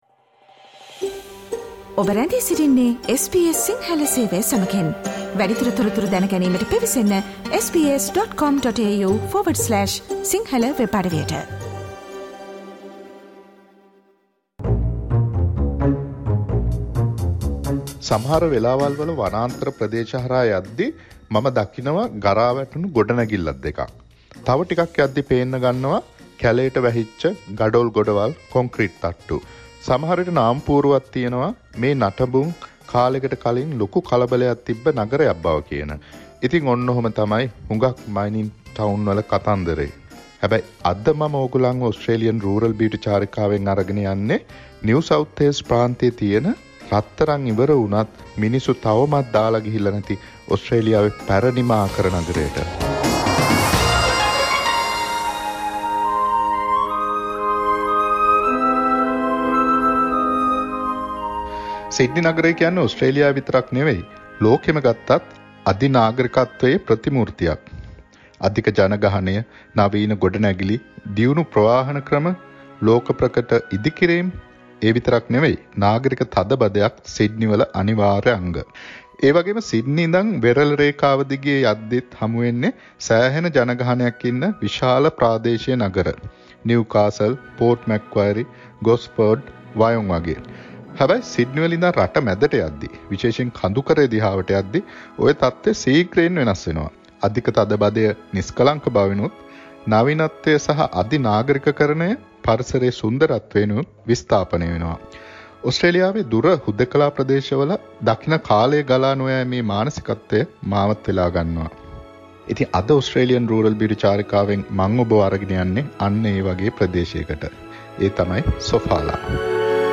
Traveling to Sofala, an oldest local town where gold mines were in Australia: SBS Sinhala Monthly Radio Journey.